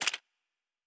Weapon 03 Foley 1.wav